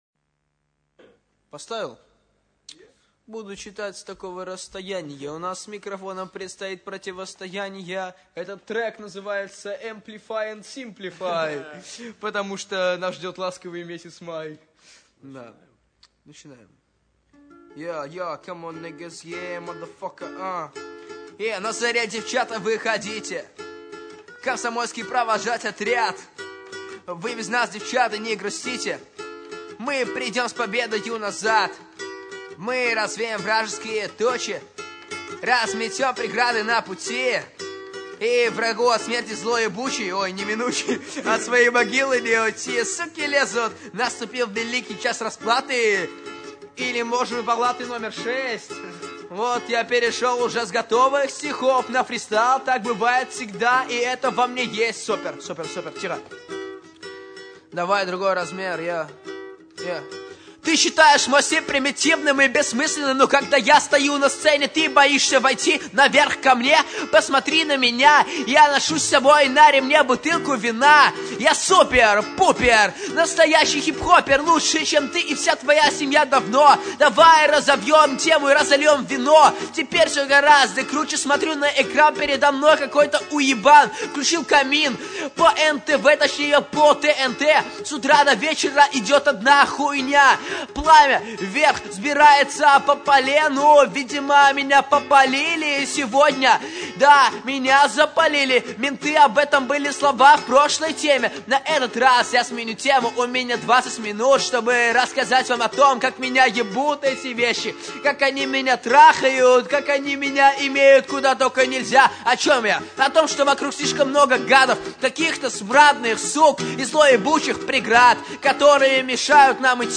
20-минутный фристайл